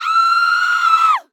Ghost Scream Sound
horror